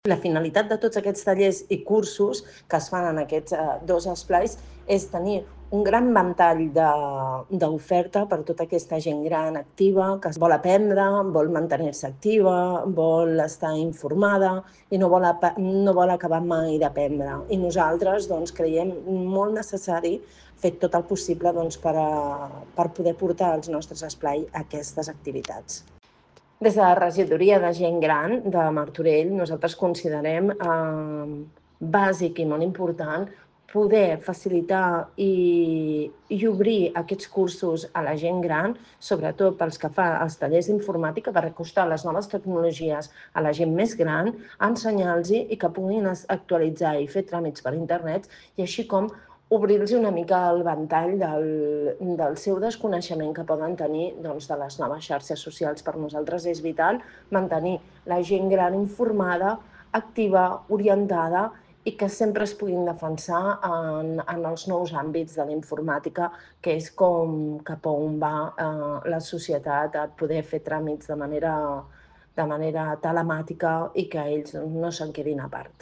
Montserrat Salas, regidora de Gent Gran de l'Ajuntament